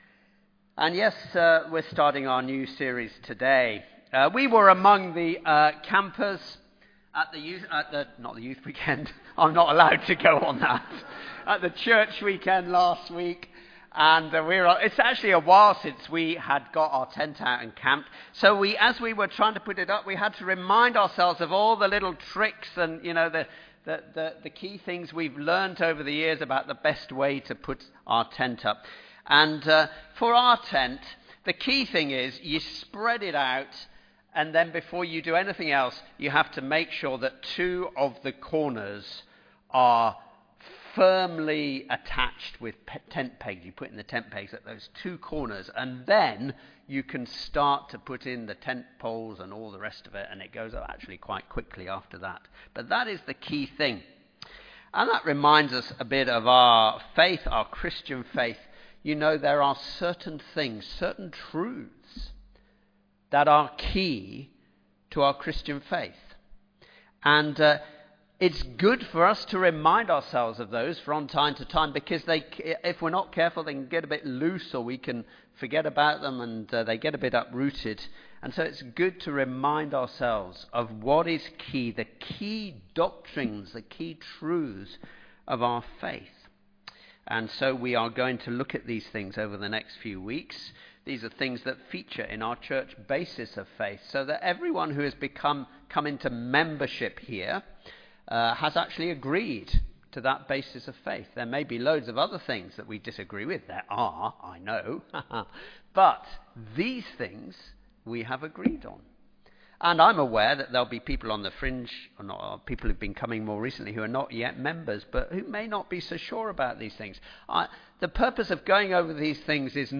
Sermon-7th-September-2025.mp3